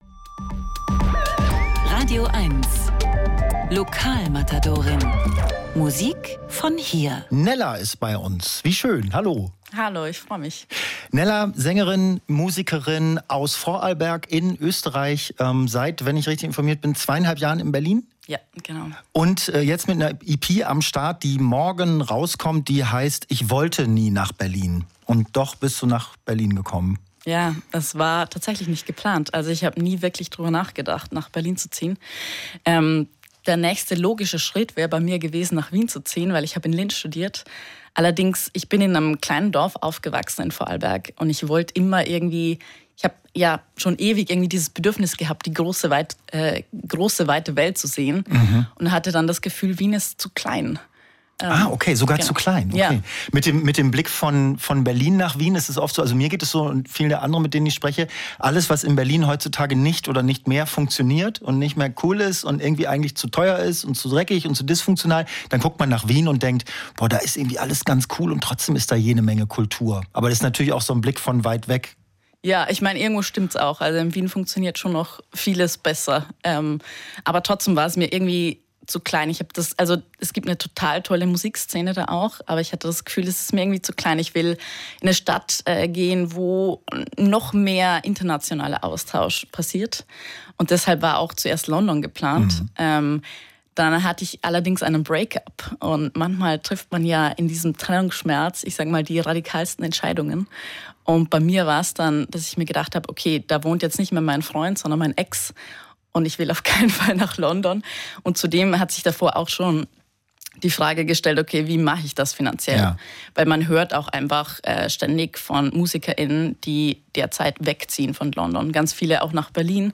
Musik-Interviews Podcast